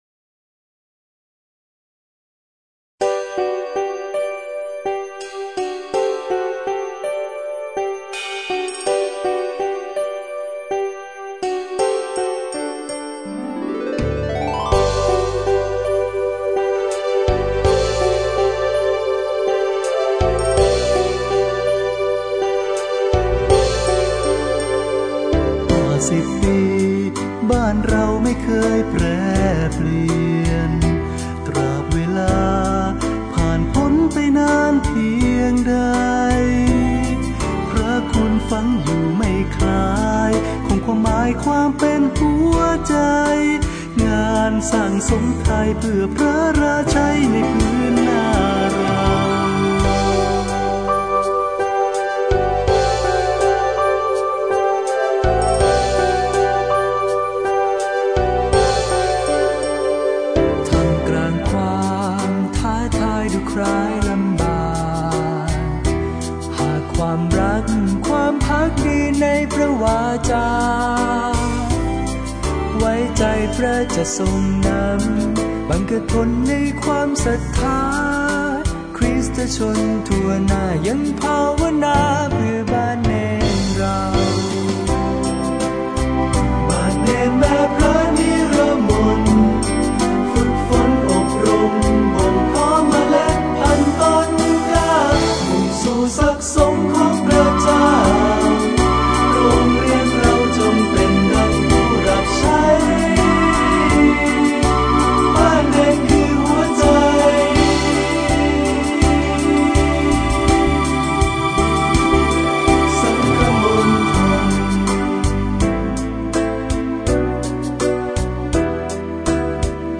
(โอกาสฉลอง 50 ปีบ้านเณรราชบุรี)
ร้องนำ